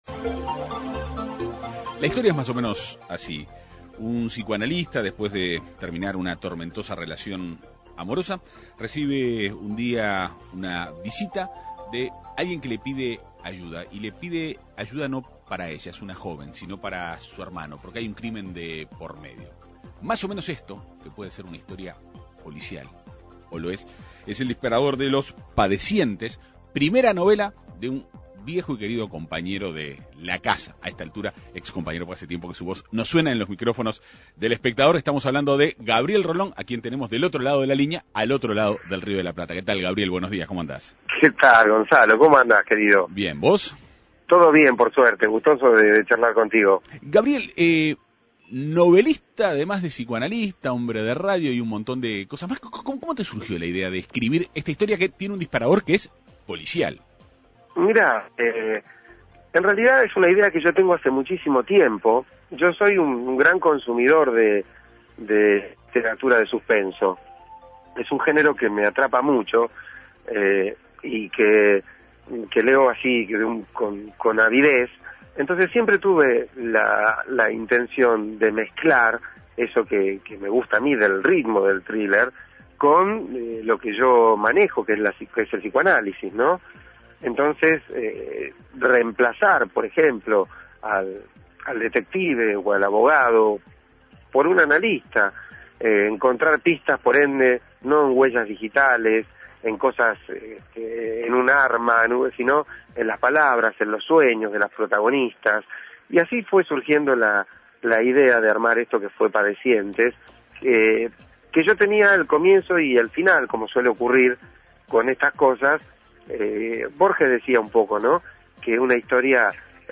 Este relato que puede ser de una historia policial -o lo es- sirve de disparador para Los Padecientes, primera novela de Gabriel Rolón, quien conversó en la Segunda Mañana de En Perspectiva.